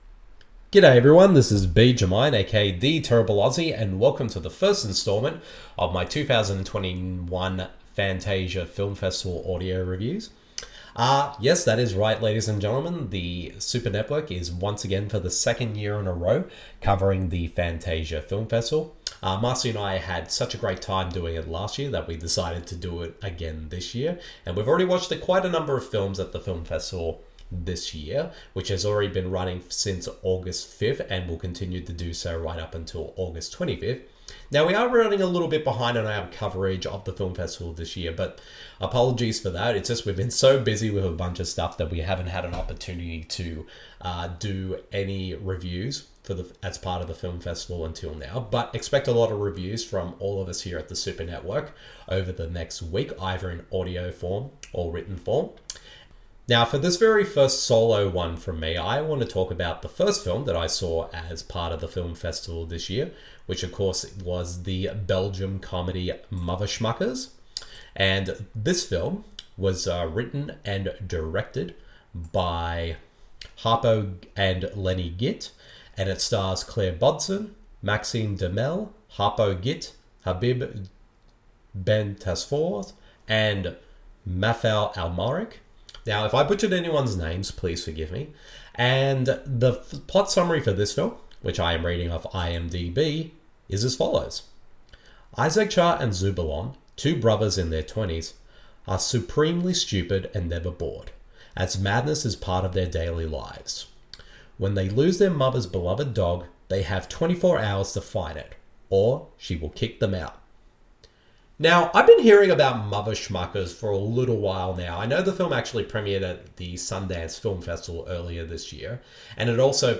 The following review of the film is in an audio format.